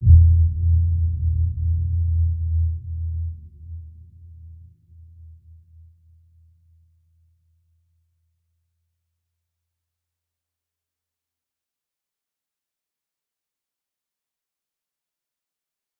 Dark-Soft-Impact-E2-mf.wav